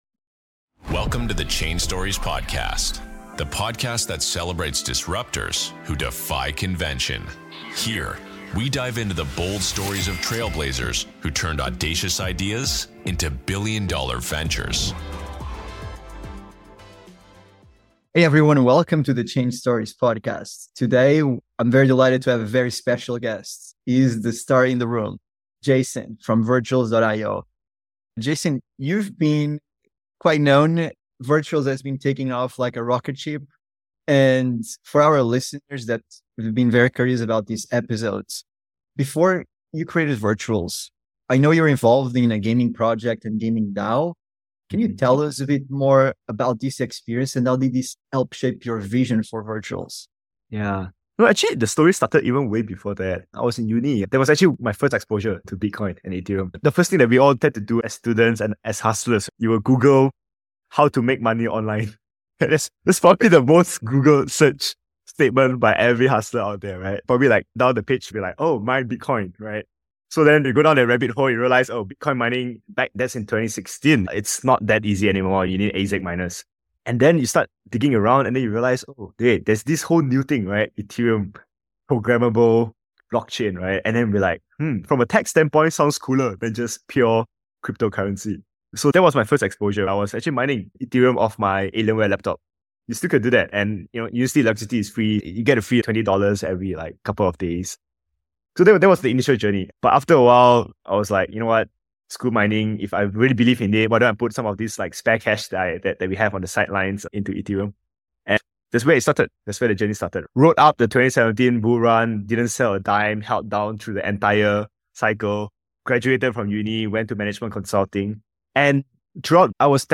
Creating a Society of AI Agents - Interview with Virtuals Protocol